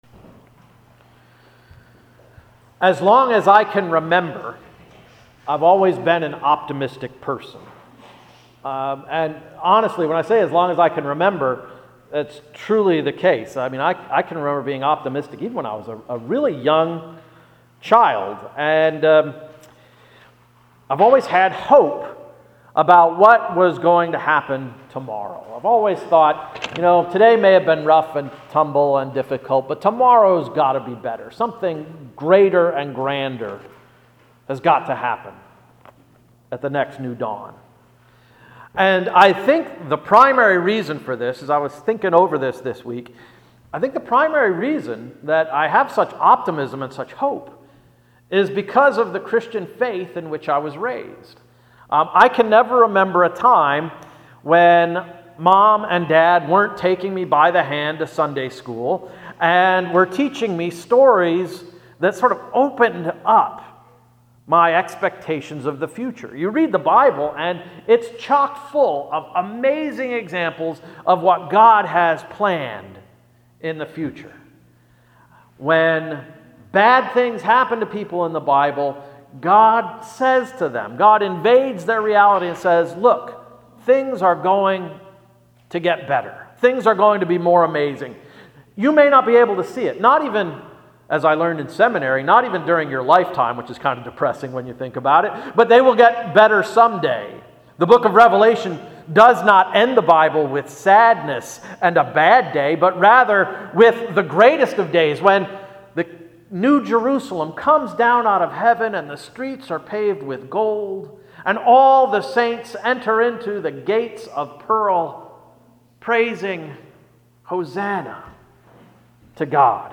July 3, 2016 Sermon– “Prone to Wander”